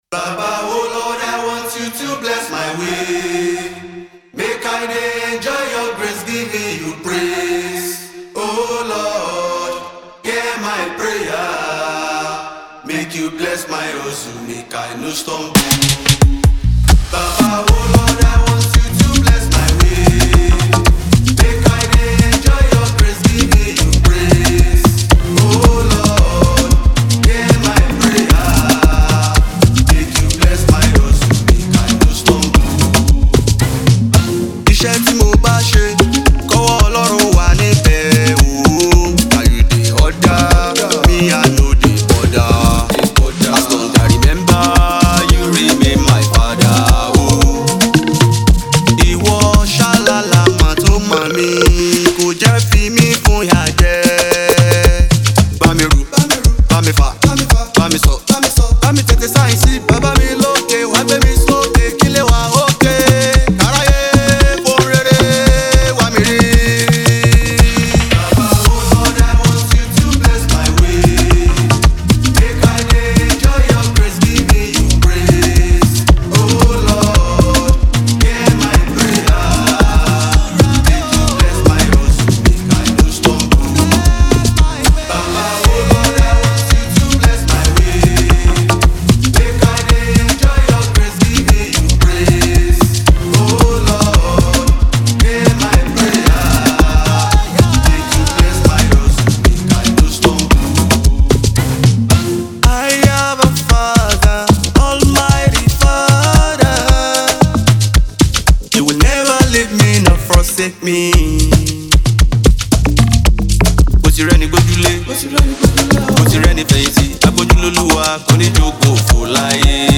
Gospel music